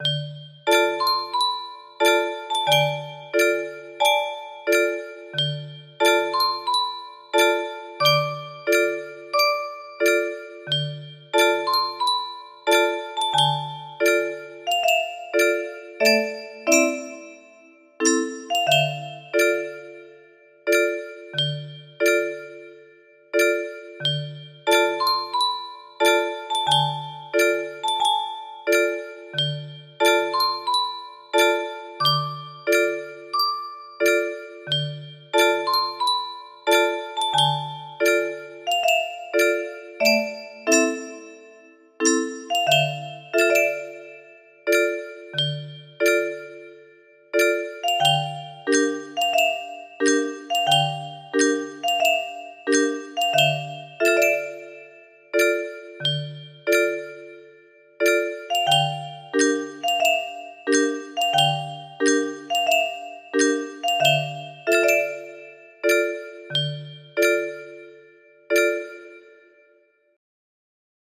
Erik Satie-Gnossienne No.1 music box melody